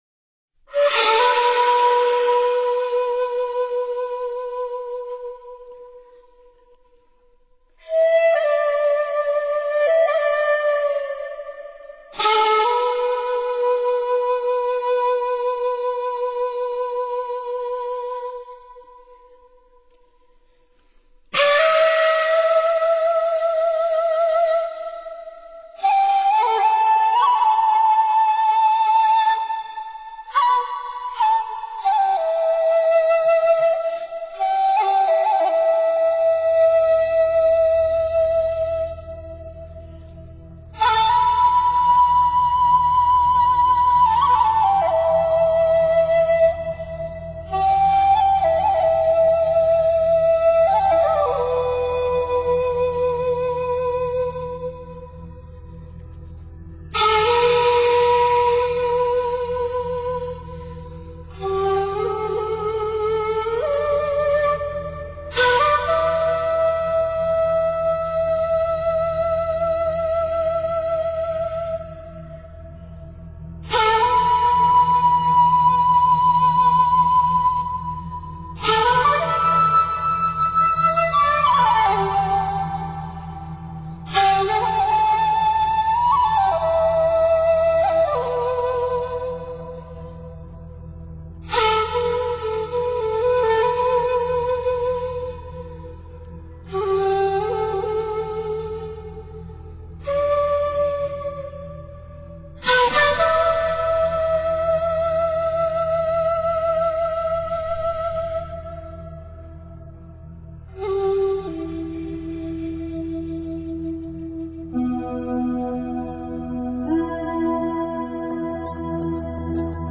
笛音虽委婉，听来却悲悯，有羽翼无痕那种隐约的苍凉。
竹笛，在本辑中有较多的运用。